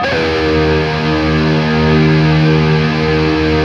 LEAD D#1 LP.wav